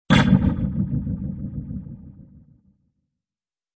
Descarga de Sonidos mp3 Gratis: disparo 17.